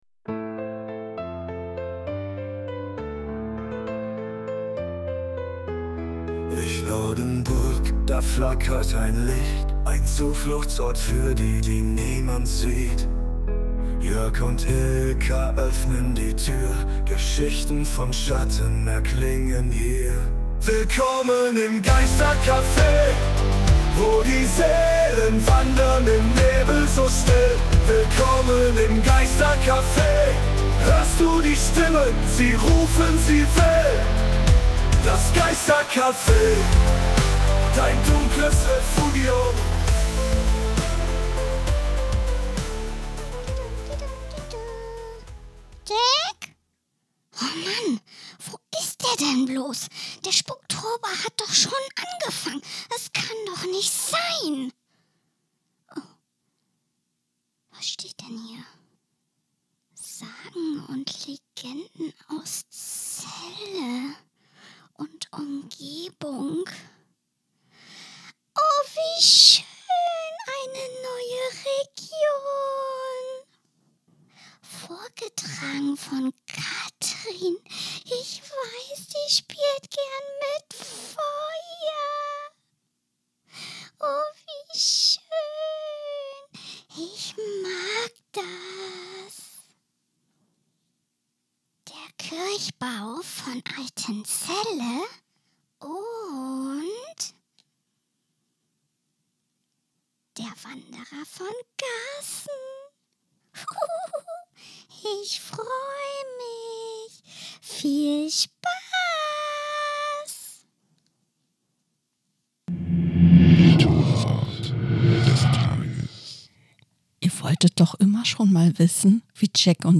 In der vierten Episode des Spuktober 2025 werfen wir abermals einen Blick auf das, was euch in den kommenden Nächten erwartet, und starten mit einer Geschichte, die euch garantiert das Blut in den Adern gefrieren lässt. Dazu gesellen sich einige unserer Freunde, die den Spuktober mit ihren Stimmen noch lebendiger und gruseliger machen.